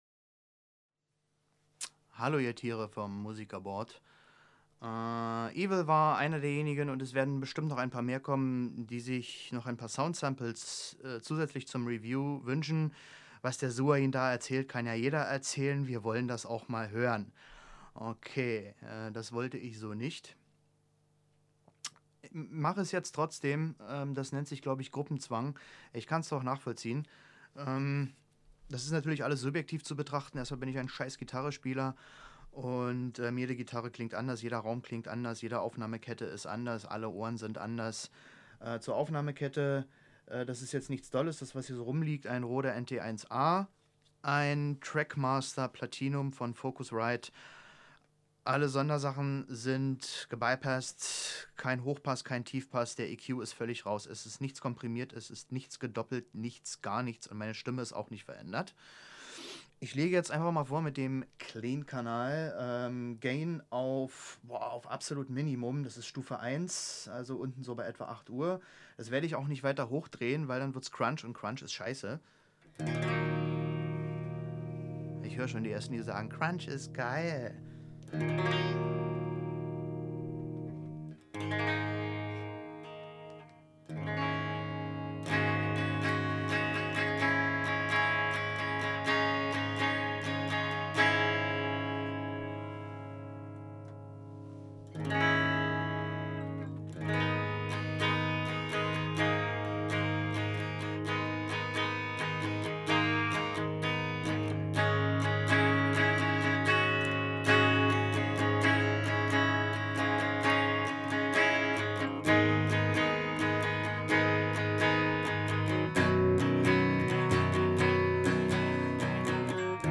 Hab mich selbstredend laufend verspielt und hab nun nicht 3 Stunden damit verbracht die beste Micposition zu finden.
samples sind auch gut, leider sehr viele anschlaggeräusche mit drauf, aber ok und danke für die mühe!
Es waren ca. 15 cm. Mittig gerichtet, also zwischen Kalottenrand und Kalottenmitte.
2. Master stand auf ca. halb 9 (Stufe 2).
3. Noisegate war NICHT aktiviert.
fireball100_demo.mp3